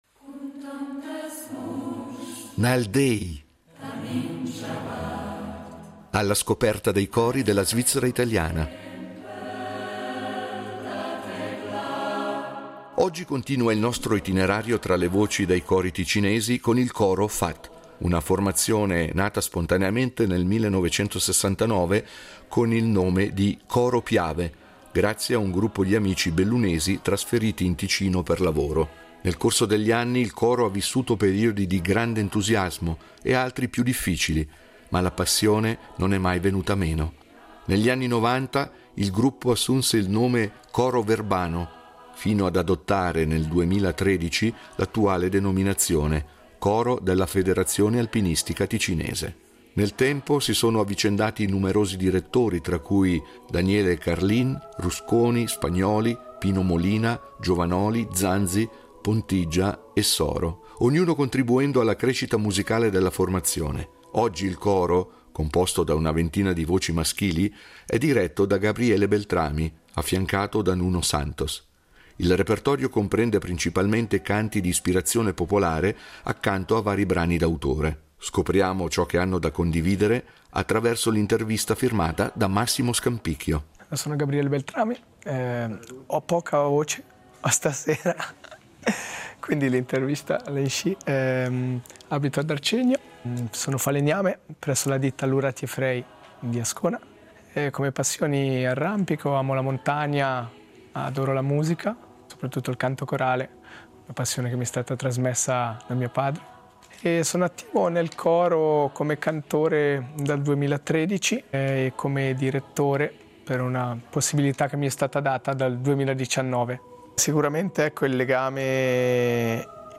Nal déi, cori della svizzera italiana
un coro maschile di una ventina di voci
con un repertorio di canti popolari e d’autore